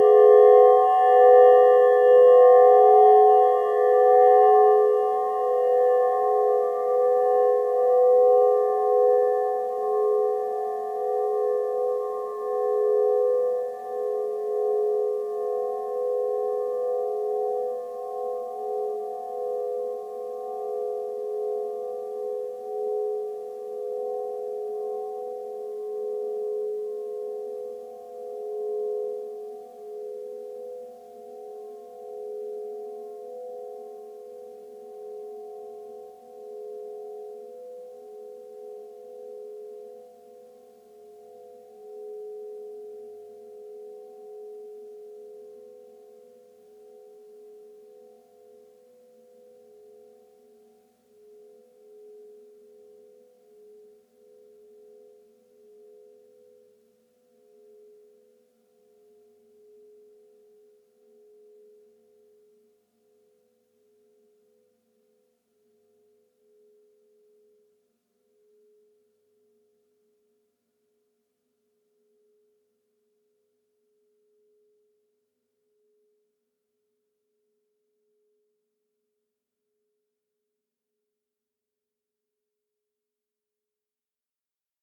Singing-bowl Esque
bell bowl cinematic ding dong ging glass gong sound effect free sound royalty free Movies & TV